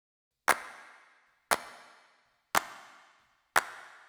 04 Clap.wav